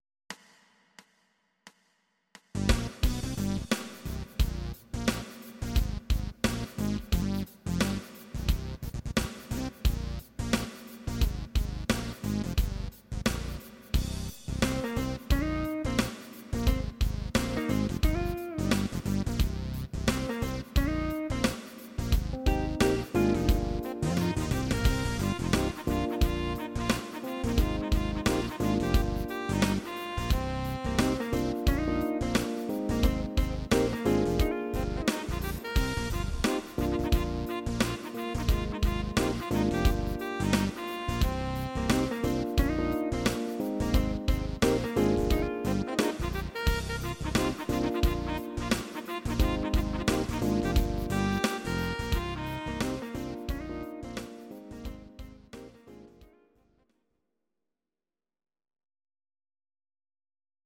Audio Recordings based on Midi-files
Pop, 2000s